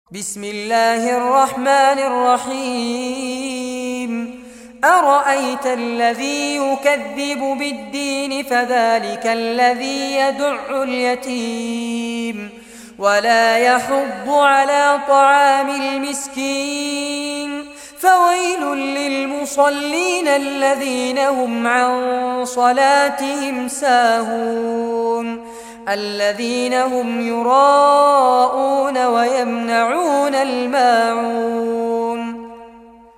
Surah Maun, listen or play online mp3 tilawat / recitation in Arabic in the beautiful voice of Sheikh Fares Abbad.
107-surah-maun.mp3